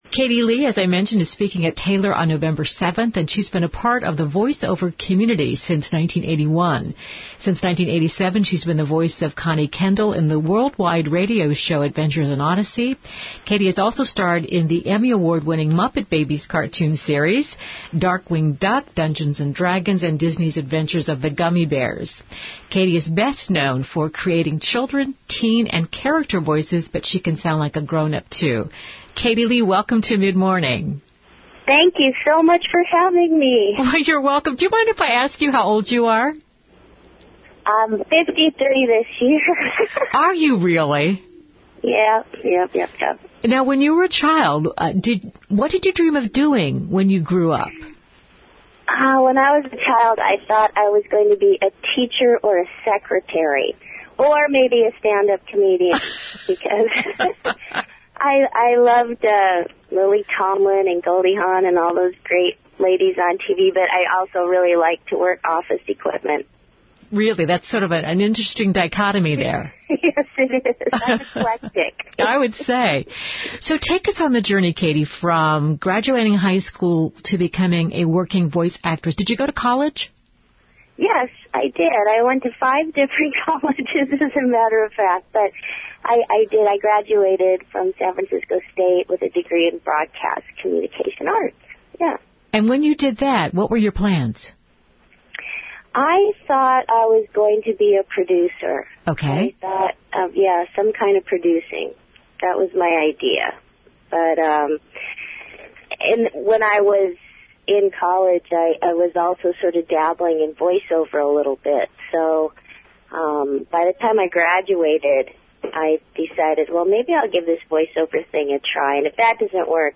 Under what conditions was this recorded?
Excerpt from Mid-Morning segment.